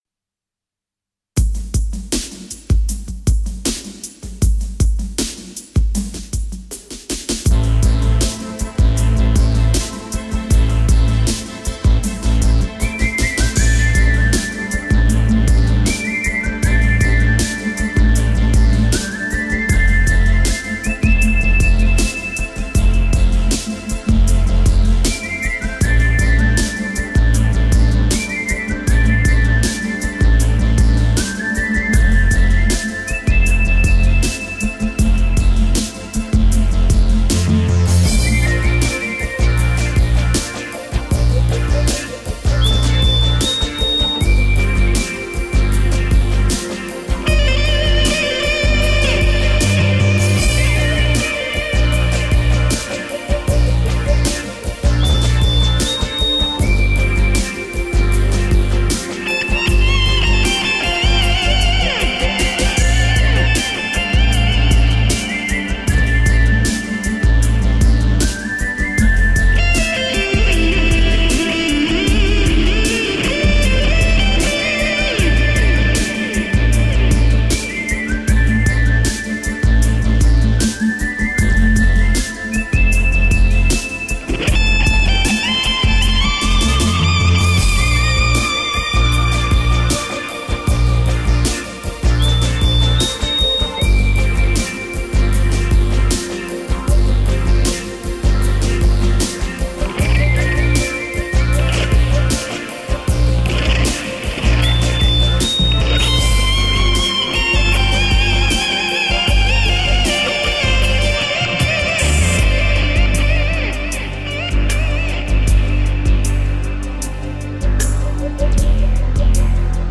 Žánr: Rock
kytarových fantazií z pomezí rocku a jazzu